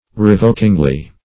revokingly - definition of revokingly - synonyms, pronunciation, spelling from Free Dictionary Search Result for " revokingly" : The Collaborative International Dictionary of English v.0.48: Revokingly \Re*vok"ing*ly\, adv.